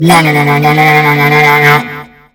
cries
VENOMOTH.mp3